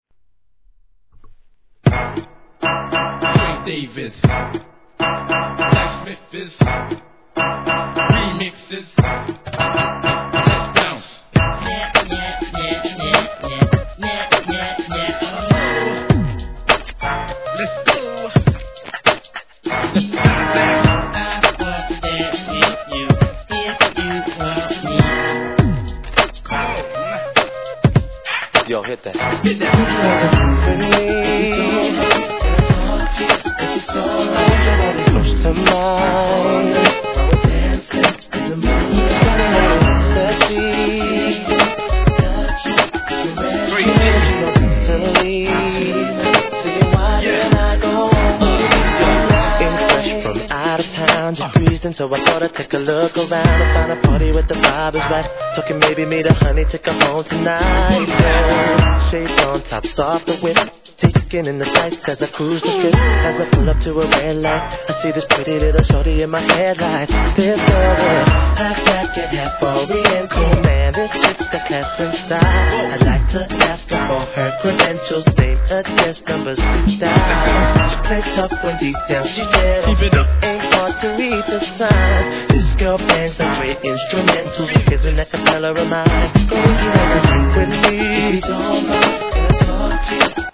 HIP HOP/R&B
WHITE ONLYでの激アツREMIX!!